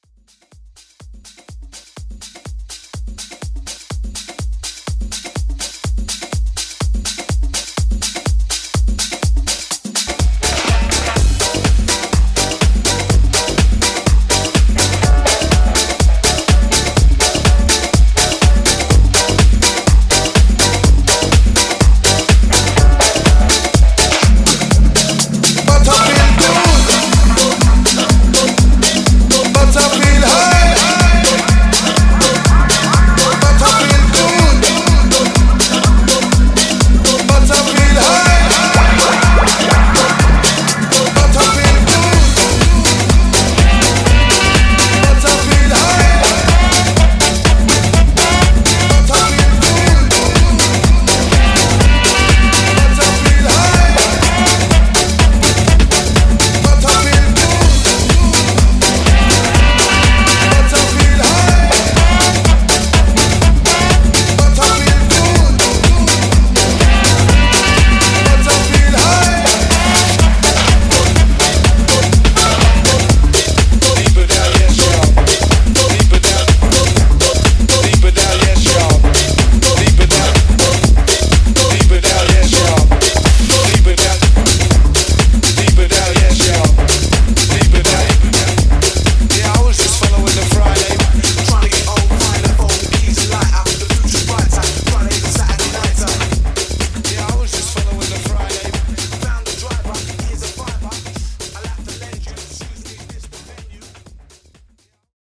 Dance House)Date Added